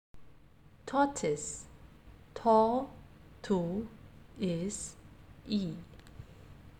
2. Tortoise – ‘tor’ ‘to’ ‘is’ ‘e’ (